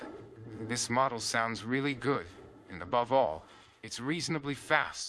brain_generated_example.wav